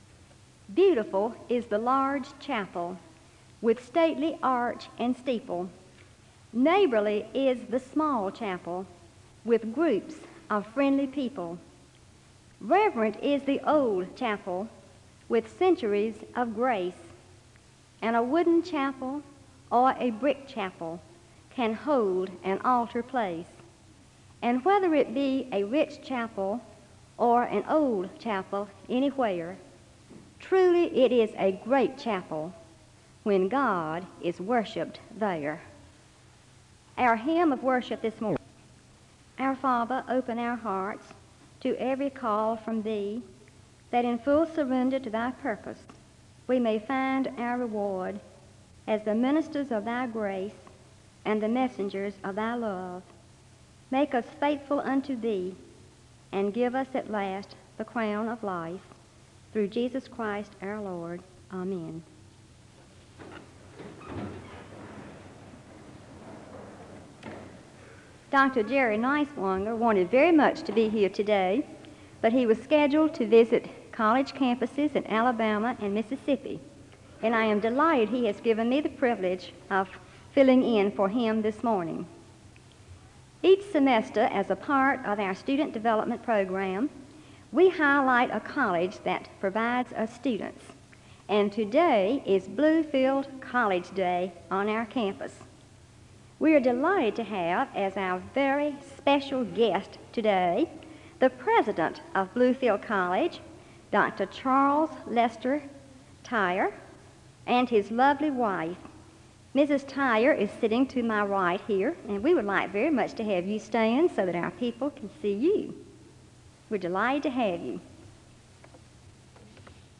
The service begins with a poem about chapels and a word of prayer (00:00-01:04).
The choir sings a song of worship (03:58-07:38).
Preaching